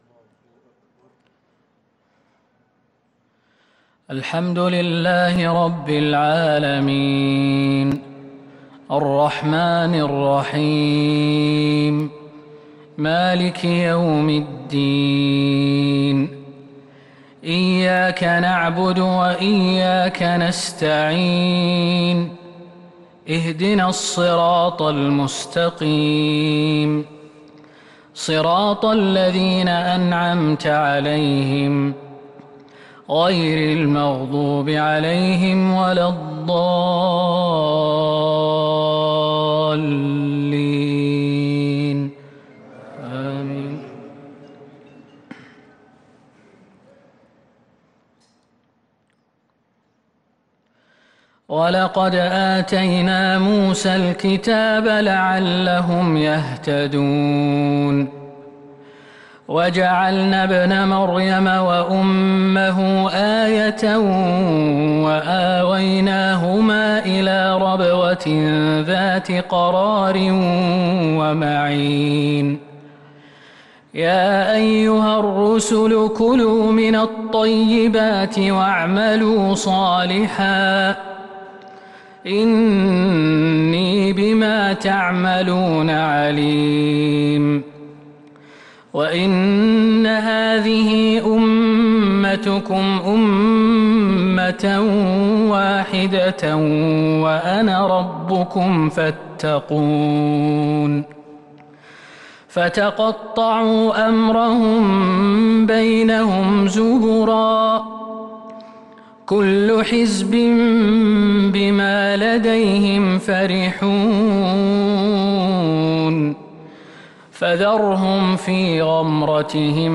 صلاة العشاء للقارئ خالد المهنا 10 محرم 1443 هـ